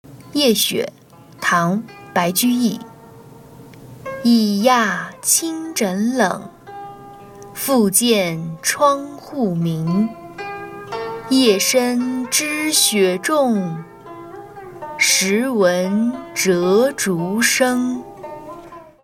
Le voici lu en mandarin